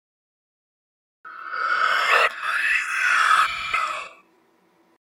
Scary Vocie - Let Me In
Category: Sound FX   Right: Both Personal and Commercial
Tags: meme sound; meme effects; youtube sound effects;